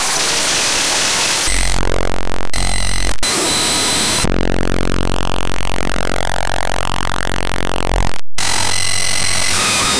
a single chirp made by a human
a-single-chirp-made-by-k27ucnwo.wav